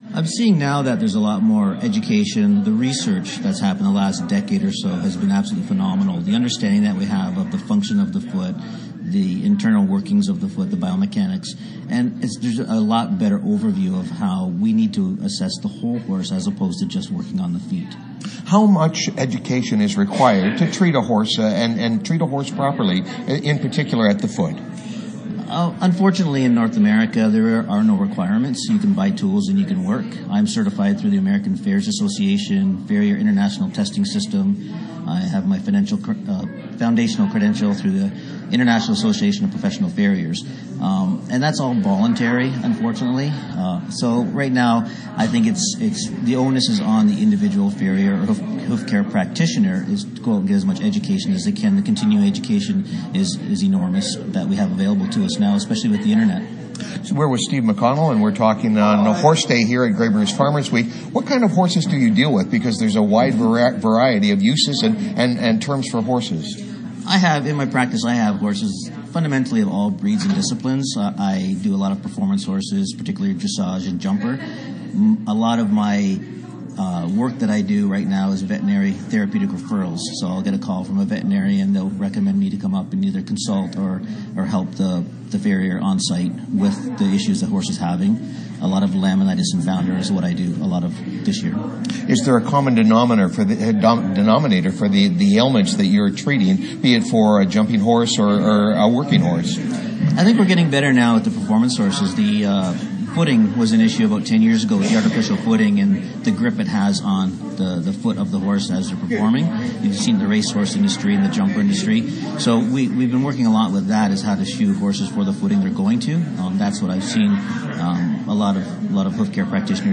Horse Day was one of the theme days at the 2025 Grey Bruce Farmers Week in Elmwood.